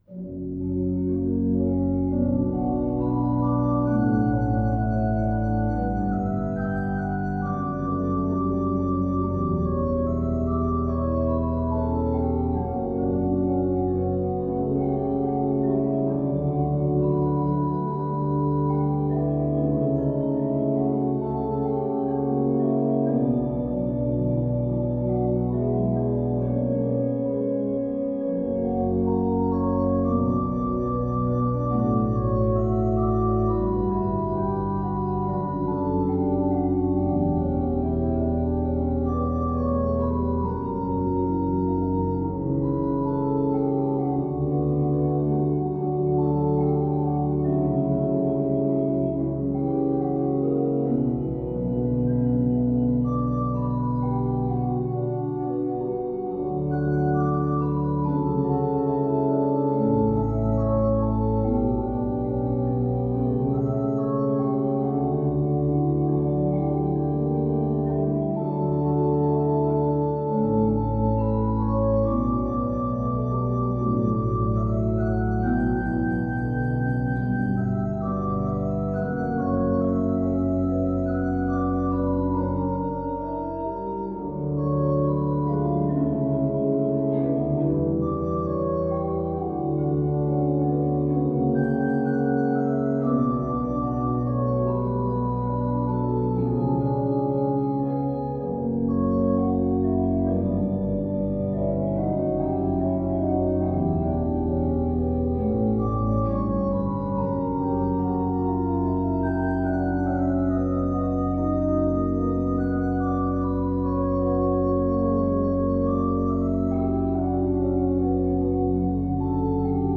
Nach mehreren Umbauten und klanglichen Veränderungen wurde die Orgel Anfang der 70er Jahre abgebaut und 1973 durch eine neobarocke Orgel der Firma Kreienbrink aus Osnabrück ersetzt.
Orgelmusik